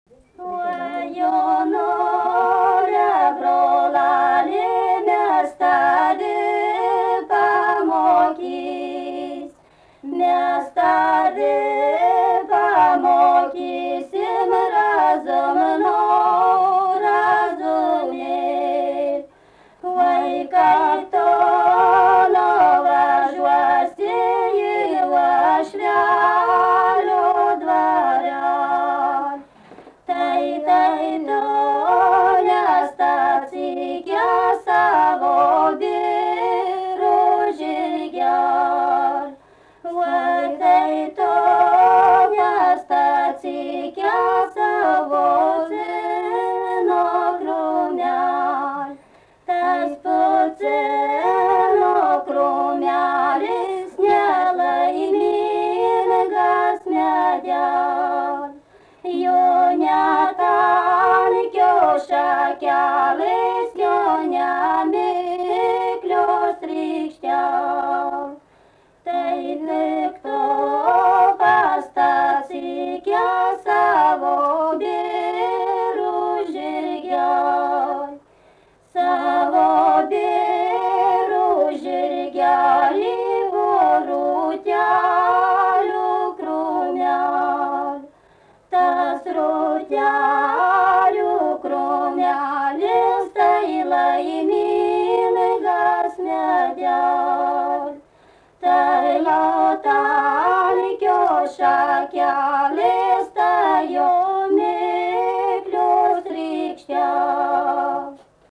daina
Kriokšlys
vokalinis
2 balsai